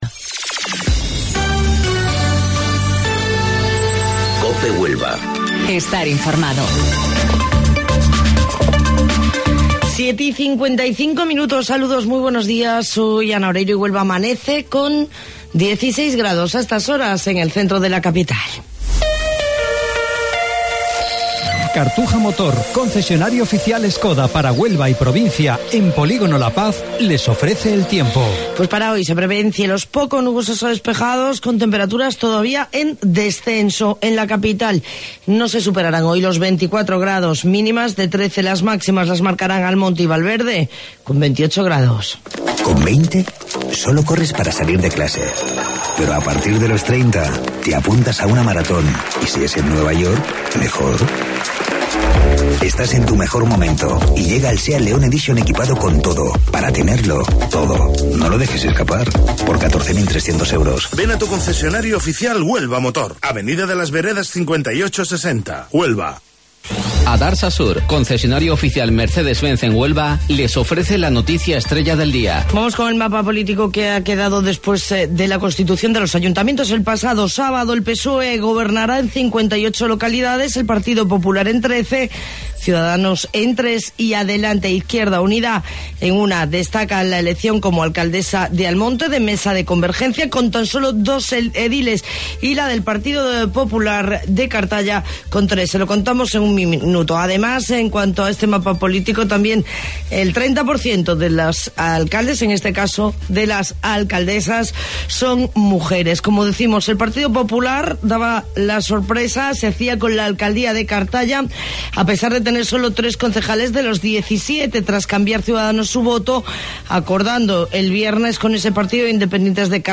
AUDIO: Informativo Local 07:55 del 17 de Junio